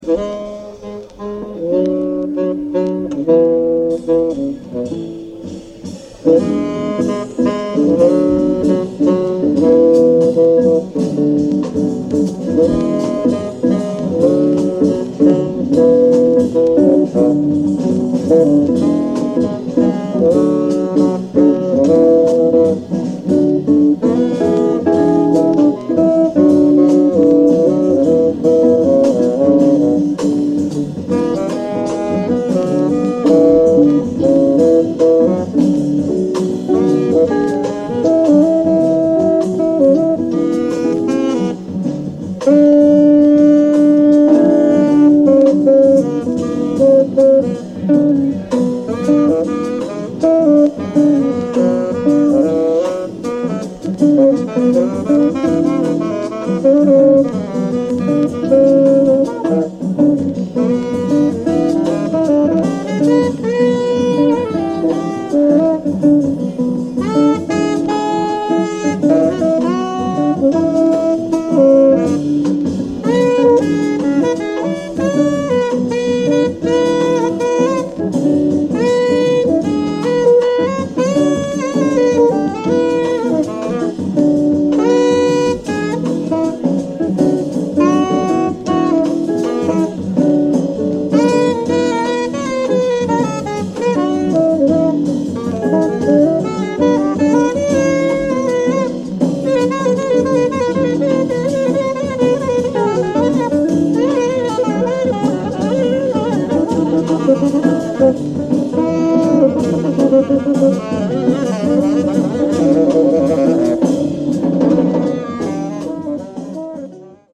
•Two 1965 New York Concerts